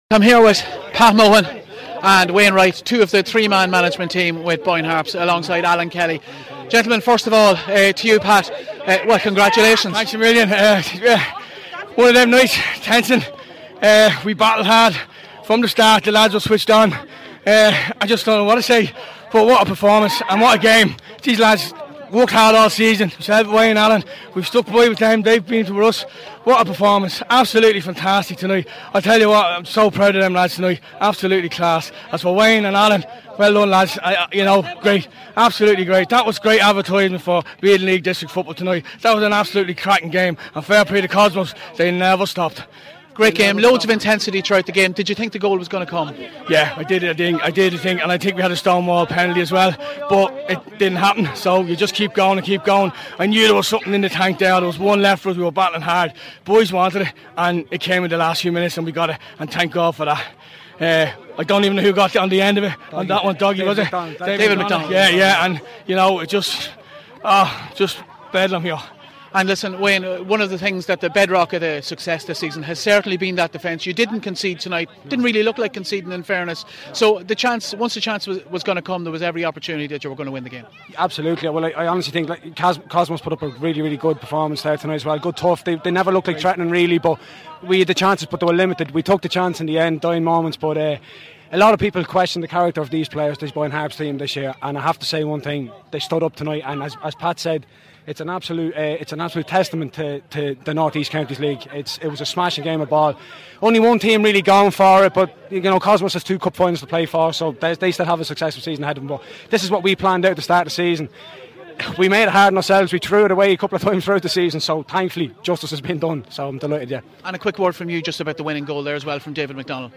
Members of the victorious Boyne Harps management team speak